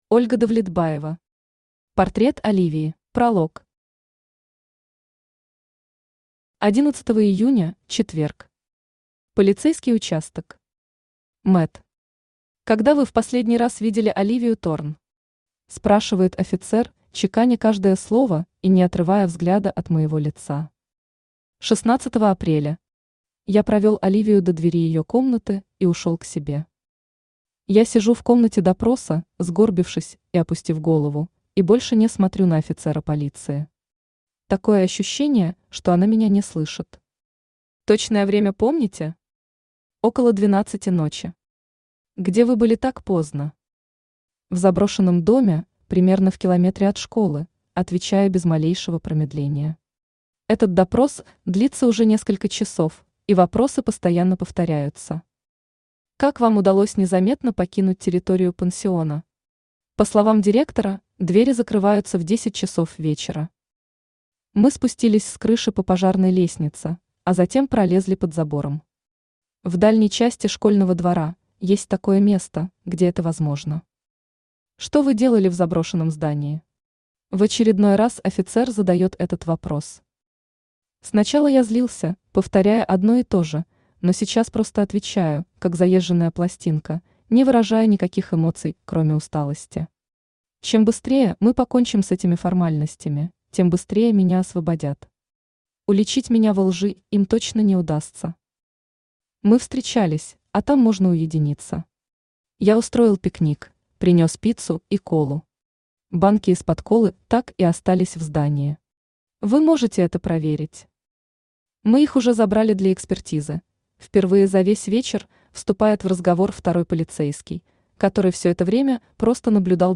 Аудиокнига Портрет Оливии | Библиотека аудиокниг
Aудиокнига Портрет Оливии Автор Ольга Давлетбаева Читает аудиокнигу Авточтец ЛитРес.